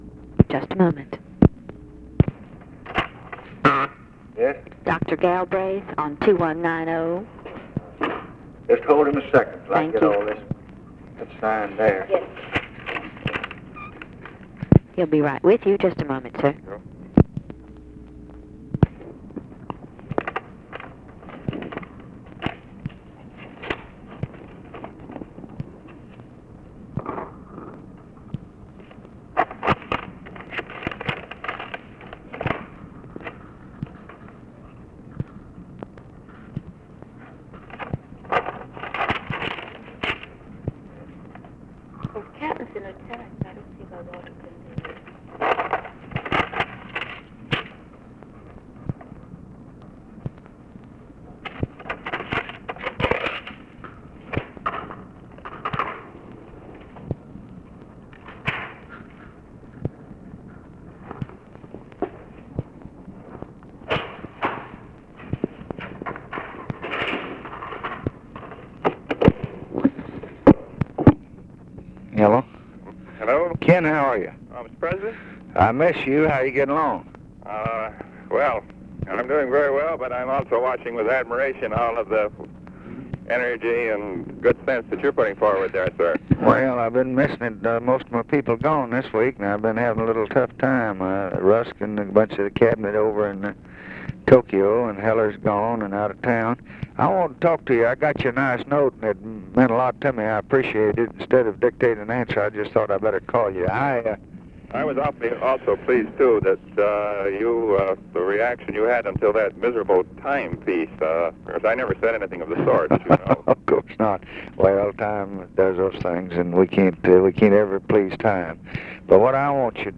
Conversation with JOHN KENNETH GALBRAITH, January 29, 1964
Secret White House Tapes